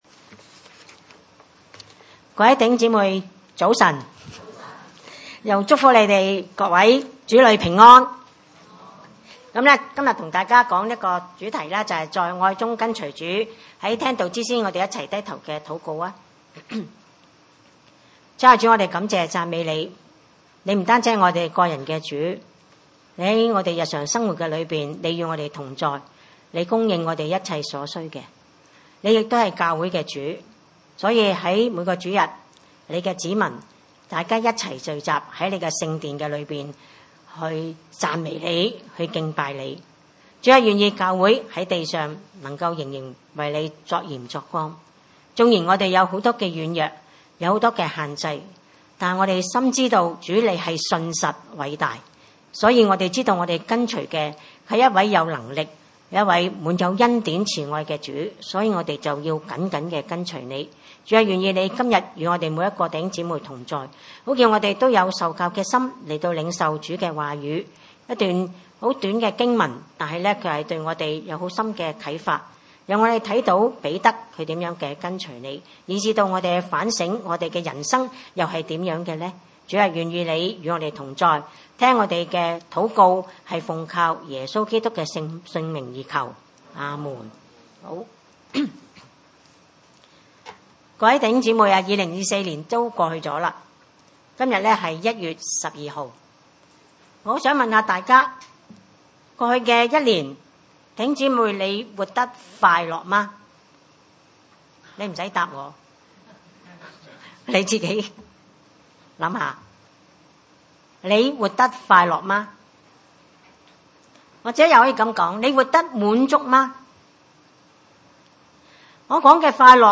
經文: 約翰福音21:15-19 崇拜類別: 主日午堂崇拜 15.